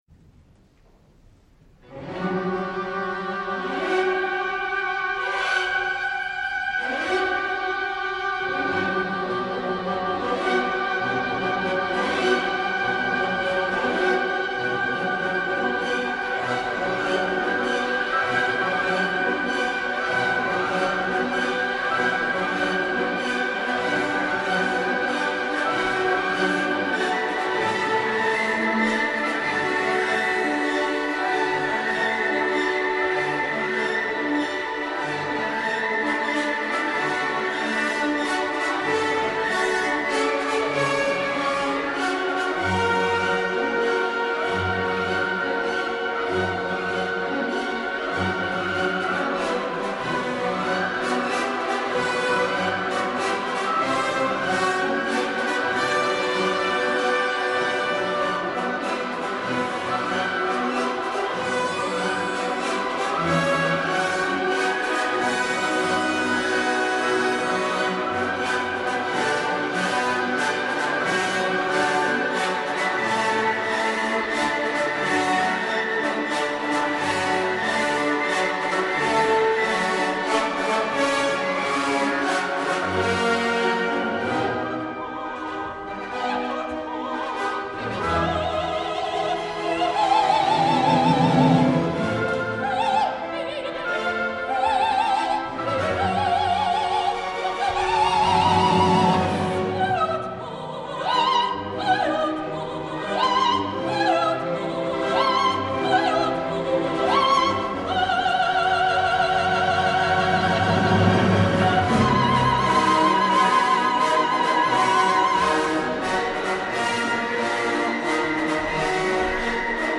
El tercer acte s’inicia amb la famosa i maltractada cavalcada i l’any passat Thielemann ja es va treure de la màniga un ritardando espectacular i preciós, molt criticat pel sancta sanctorum, penso que per marcar territori. Aquest any hi ha tornat i haig de comprovar-ho, però m’ha semblat que encara més marcat.
Les vuit valquíries que inicien l’acte, com ja haureu escoltat no han estat a la mateixa alçada de Herr Thielemann.
cavalcada.mp3